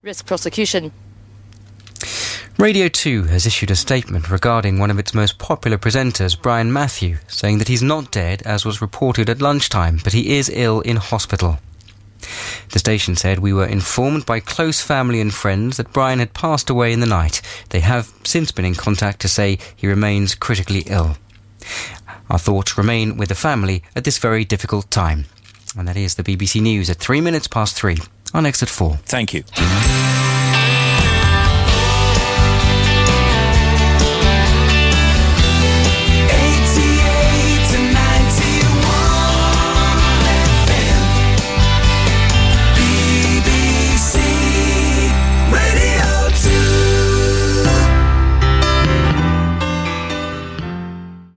The BBC Radio 2 news at 3pm on Wednesday 5th April 2017, three hours after initially reporting that presenter Brian Matthew had died.